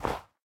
minecraft / sounds / step / snow3.ogg
snow3.ogg